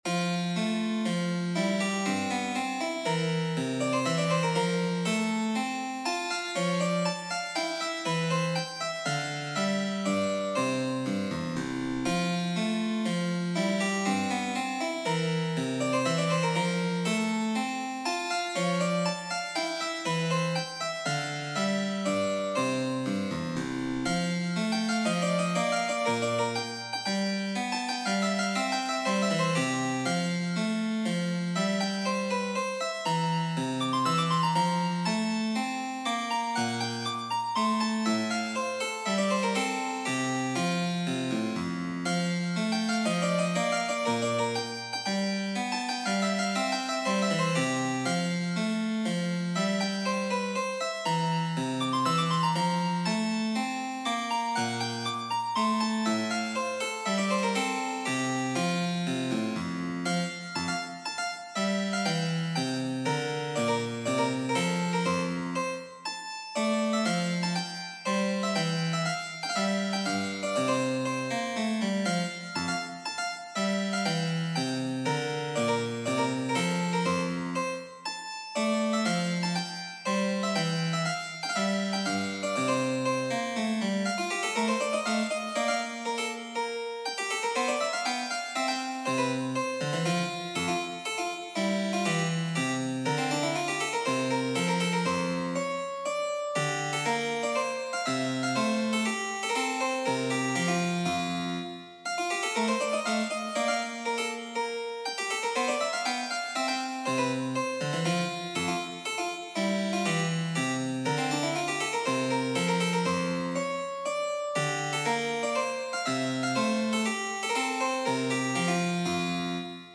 Theme and Variations
We came up with a simple structure that follows well known schema (illustrated below) for a theme in the form of a minuet, followed by a number of variations to demonstrate ways in which schema can be mani...